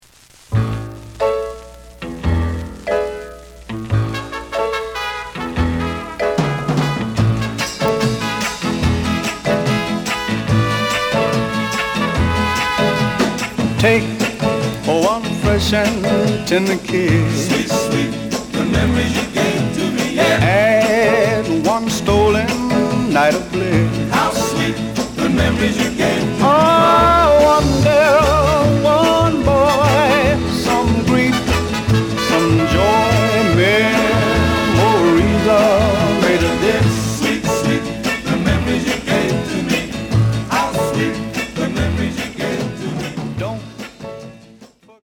The audio sample is recorded from the actual item.
●Genre: Rhythm And Blues / Rock 'n' Roll
Slight noise on beginning of both sides, but almost good.)